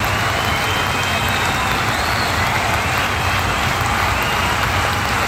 Audience.wav